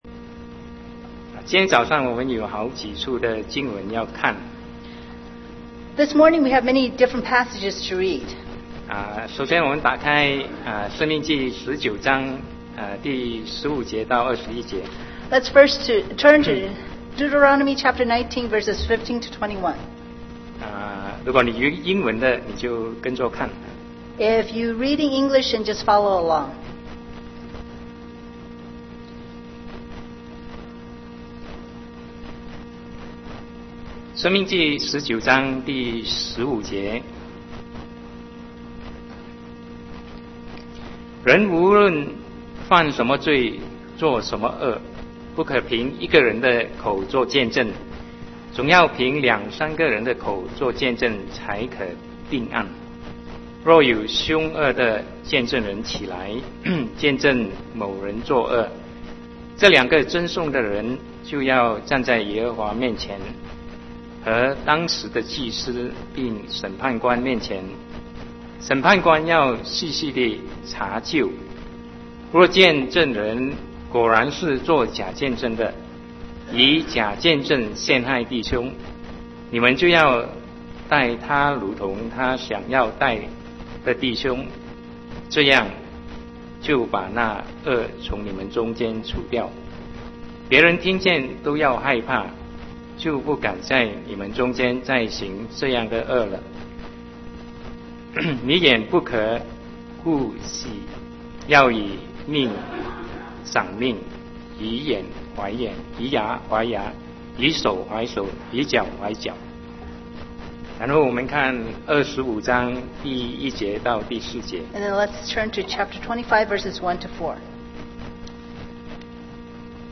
Sermon 2010-10-31 The Functions of the Law (2)